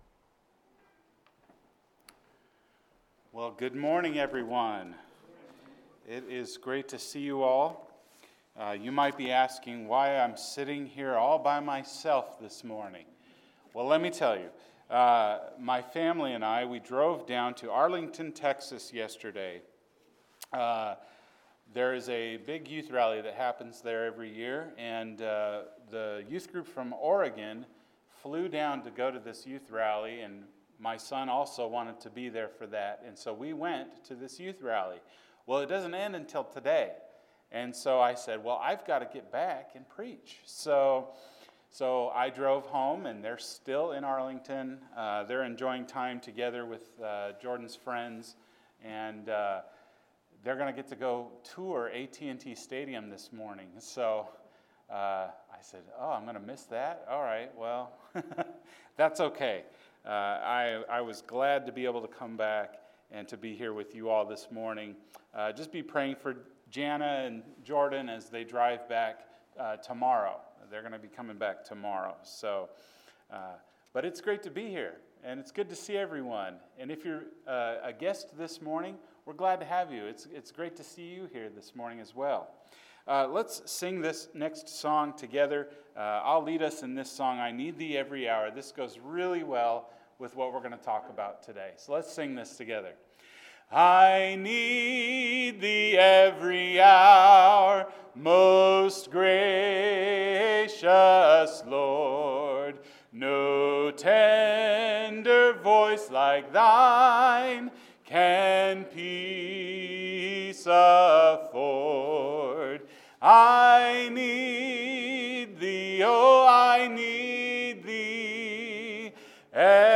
The Beatitudes – Poor in Spirit – Sermon